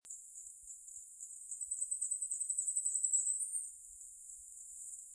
48-1麟趾山口2012mar28火冠戴菊1.mp3
物種名稱 火冠戴菊鳥 Regulus goodfellowi
錄音地點 南投縣 信義鄉 玉山麟趾山口
錄音環境 人工林邊緣
行為描述 鳥叫
錄音: 廠牌 Denon Portable IC Recorder 型號 DN-F20R 收音: 廠牌 Sennheiser 型號 ME 67